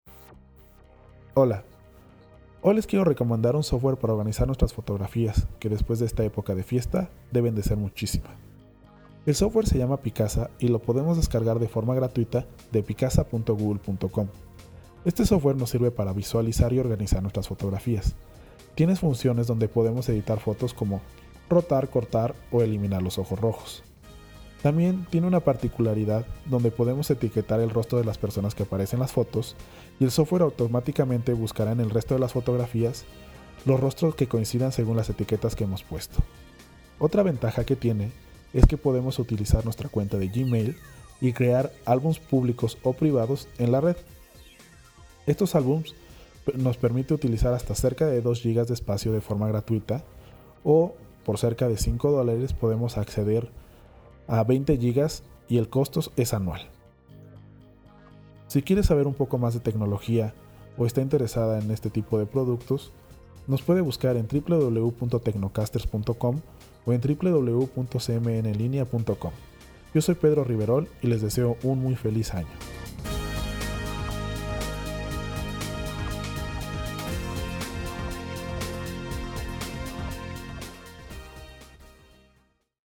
- Capsula para transmision en Radio.